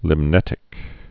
(lĭm-nĕtĭk)